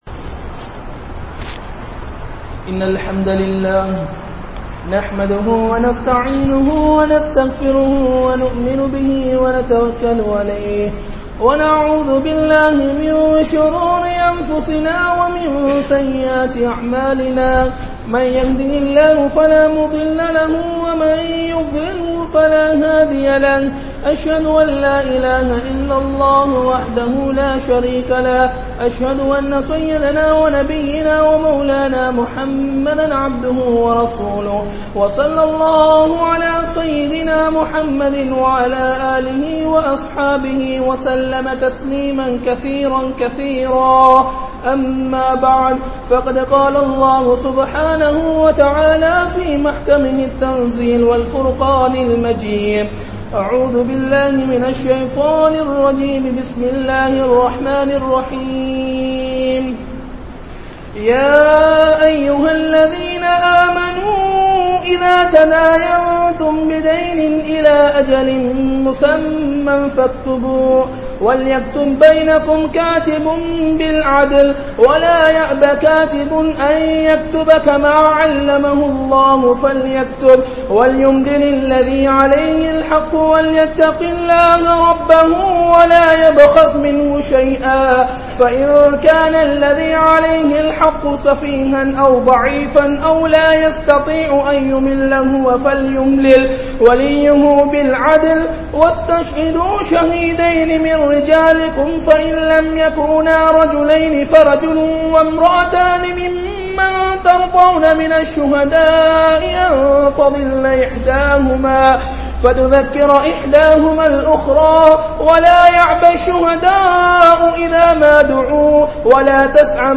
Islamiya Paarvaiel Kodukkal Vaangal (இஸ்லாமிய பார்வையில் கொடுக்கல் வாங்கல்) | Audio Bayans | All Ceylon Muslim Youth Community | Addalaichenai
Kurunegala, Mallawapitiya Jumua Masjidh